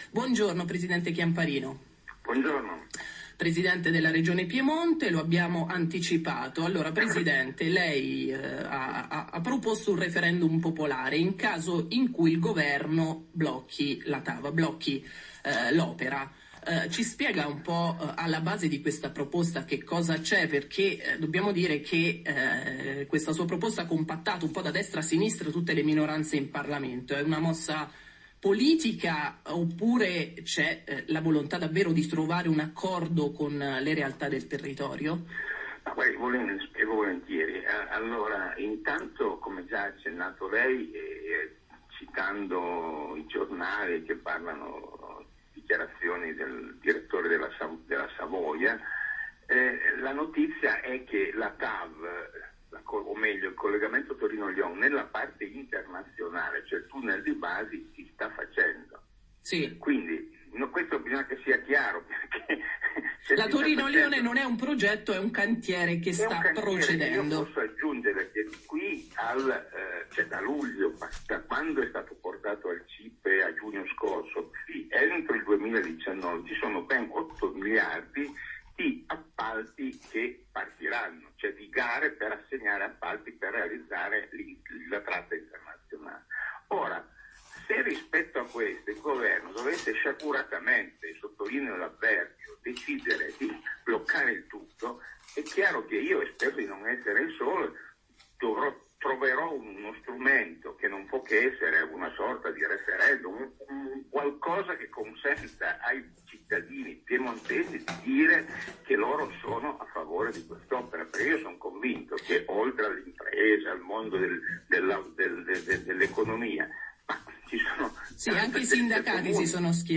Valga, per tutti, l’intervista rilasciata a “Radio anch’io” lunedì 30 luglio, di cui pubblichiamo qui la registrazione integrale.
Il nostro comincia con il “chiarire” agli ignari ascoltatori che la linea ferroviaria non è un progetto ma una realtà e aggiunge, ridacchiando soddisfatto, che i lavori procedono a gonfie vele distribuendo benessere e prosperità di qua e di là del confine. Il primo tassello di questa idilliaca ricostruzione sta nell’affermazione che, sul versante francese, lo scavo del tunnel di base è ormai iniziato e procede speditamente.